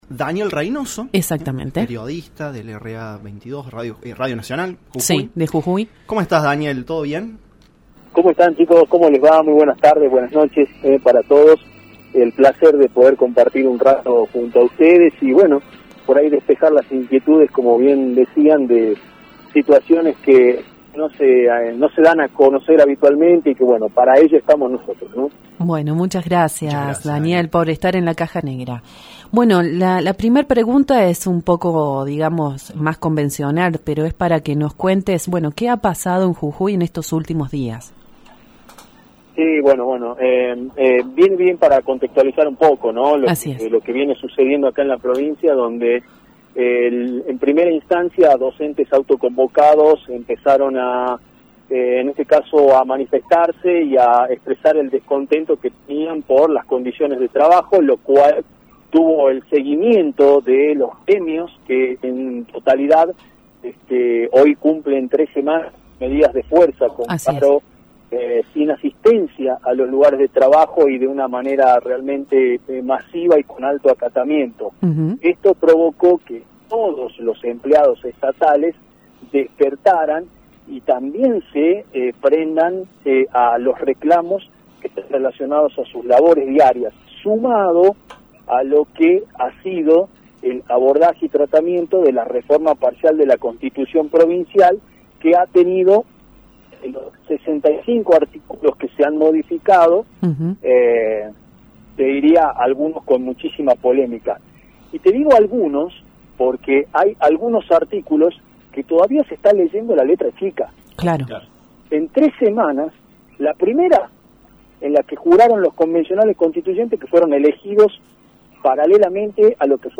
AUDIO | La Caja Negra: entrevista